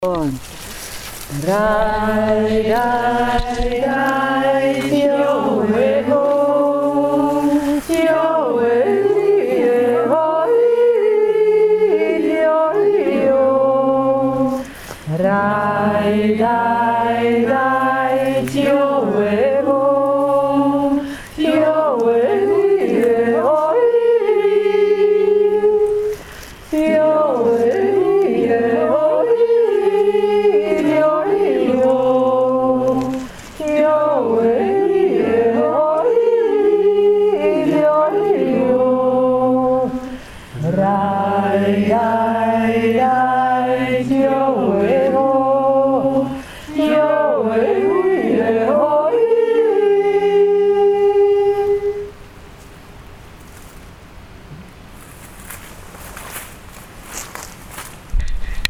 Jodeln im Einklang mit der Natur am Grundlsee 2016
So jodelten wir unsere Jodler unterm Blätterdach im "Grundseer Kurpark":